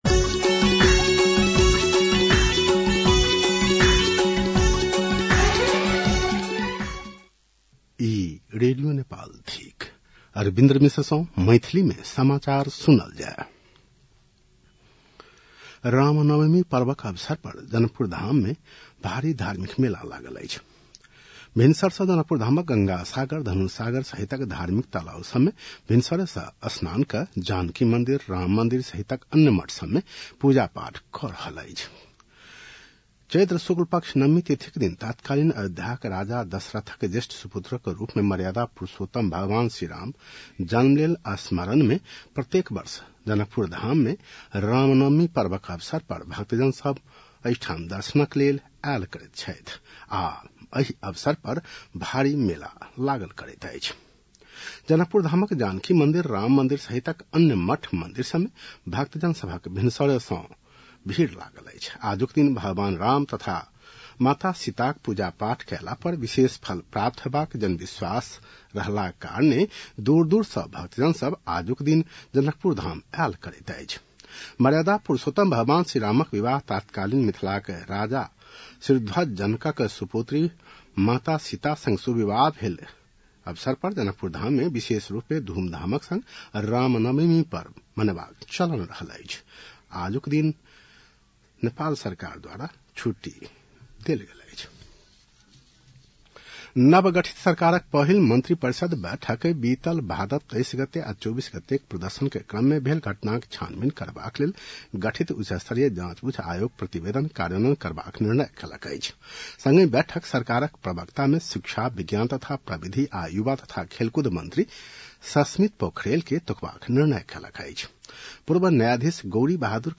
An online outlet of Nepal's national radio broadcaster
मैथिली भाषामा समाचार : १३ चैत , २०८२